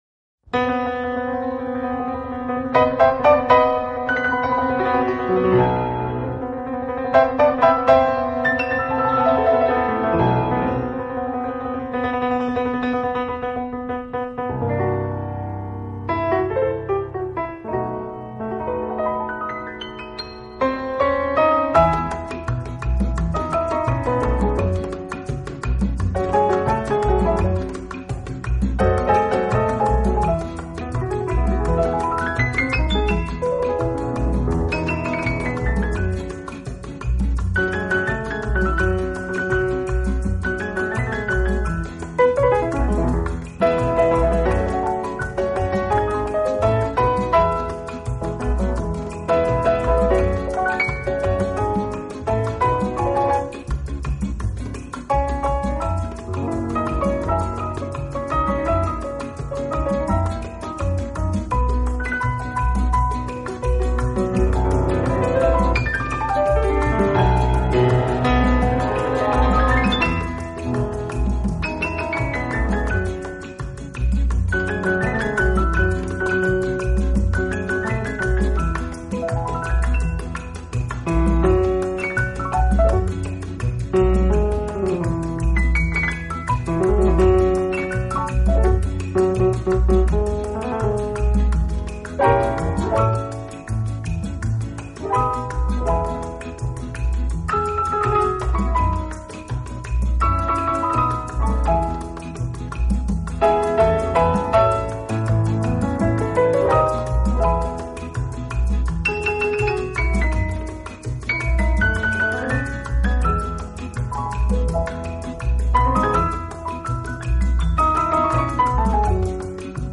演奏以轻音乐和舞曲为主。